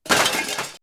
bf_metal_small.wav